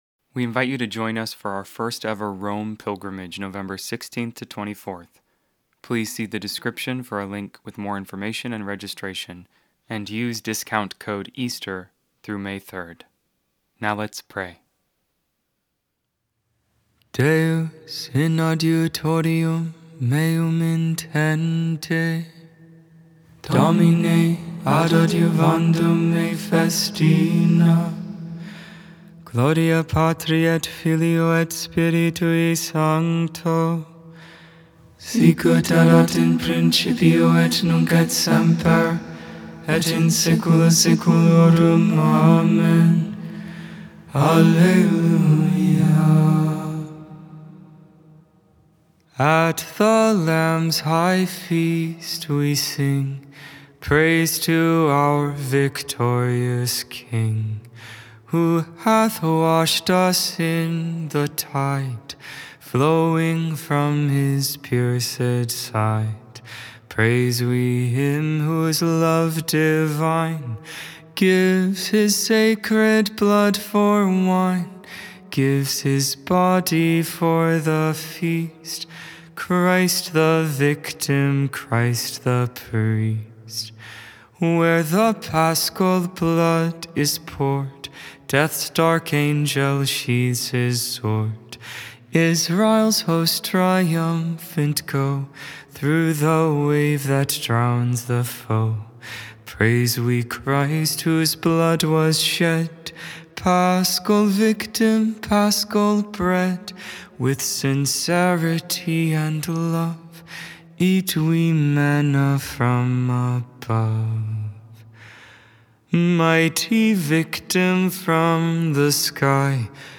Music, Christianity, Religion & Spirituality